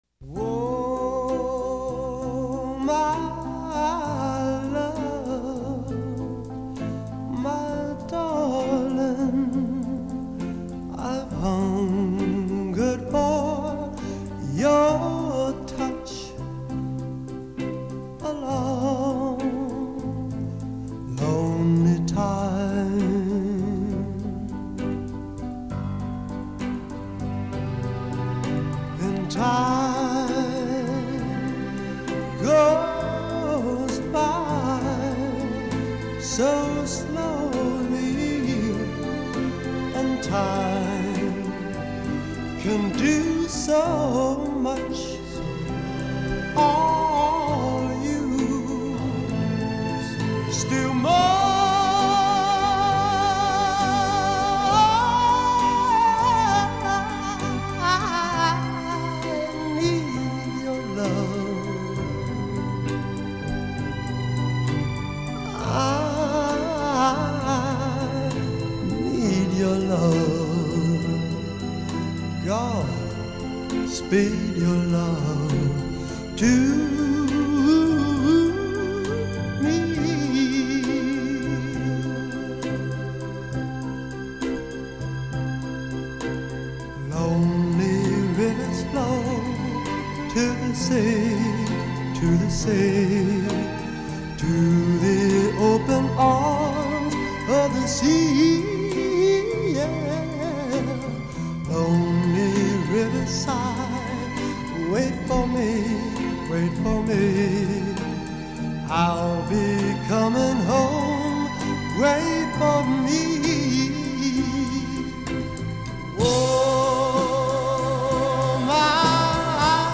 Skor Angklung